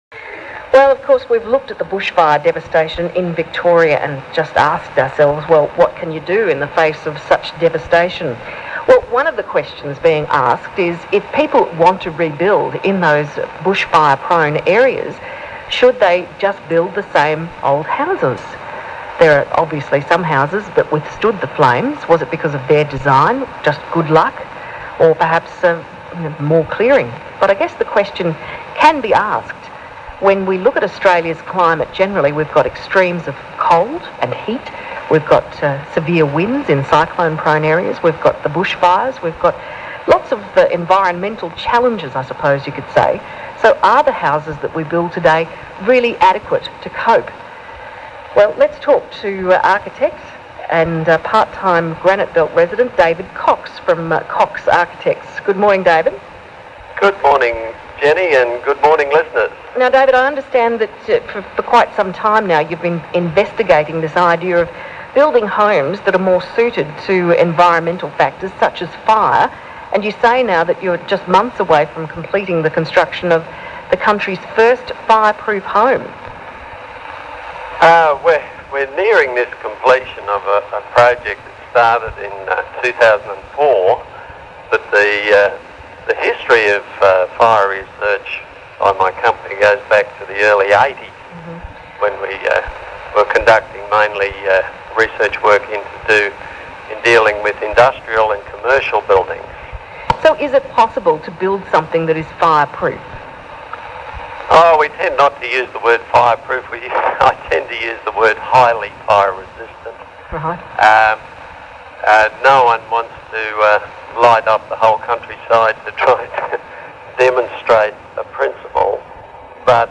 ABC-interview-5-3-09.mp3